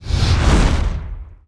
Index of /App/sound/monster/skeleton_magician
attack_3_eff.wav